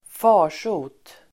Uttal: [²f'a:r_so:t]